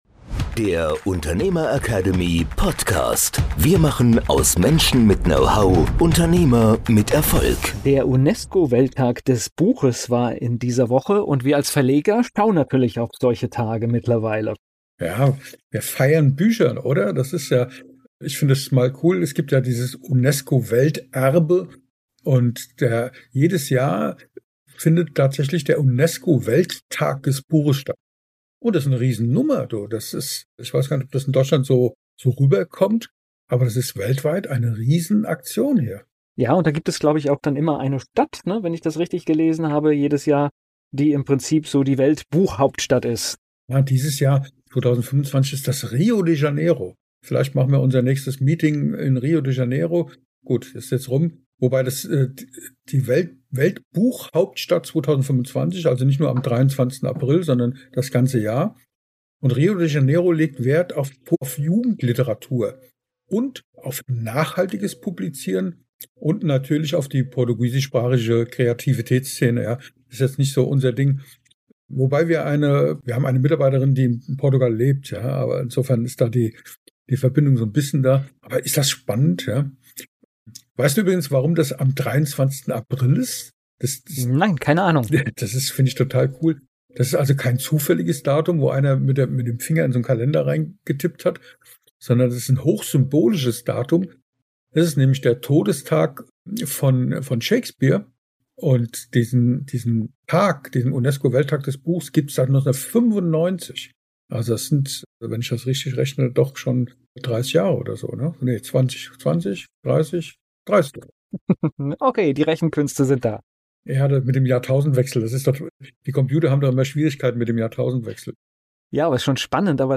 live von der Leipziger Buchmesse – Der Unternehmer-Academy Podcast